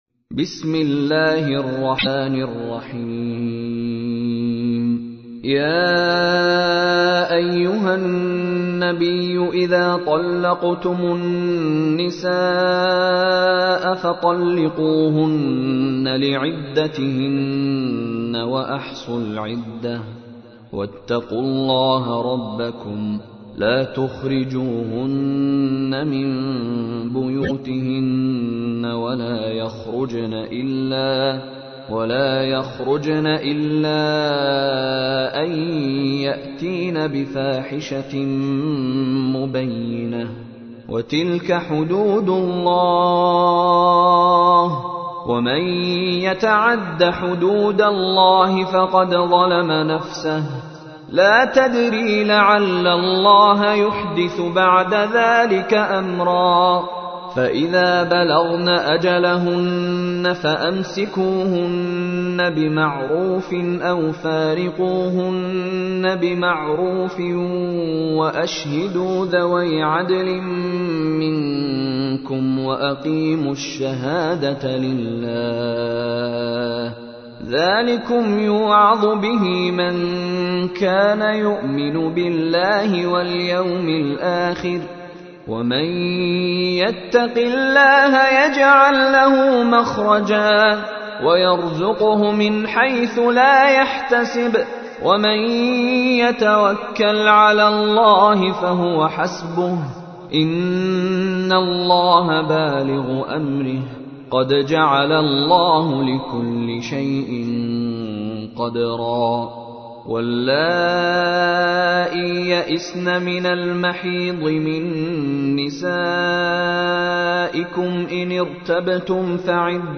تحميل : 65. سورة الطلاق / القارئ مشاري راشد العفاسي / القرآن الكريم / موقع يا حسين